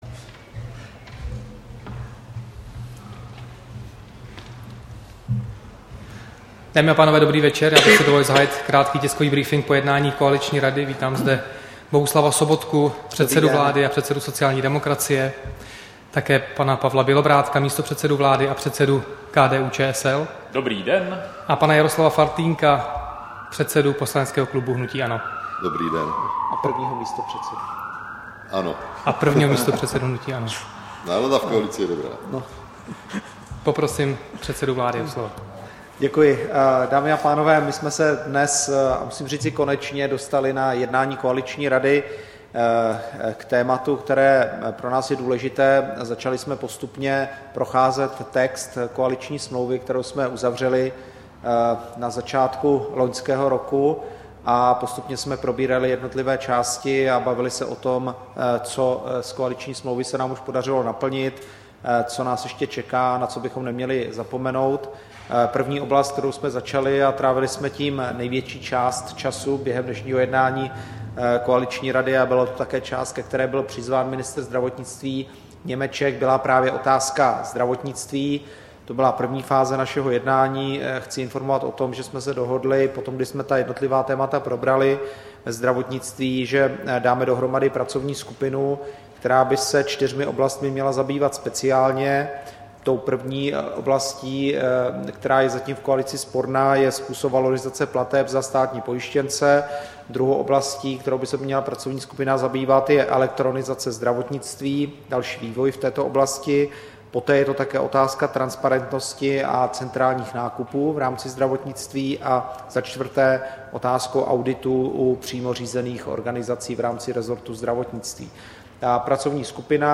Tisková konference po jednání koaliční rady, 13. května 2015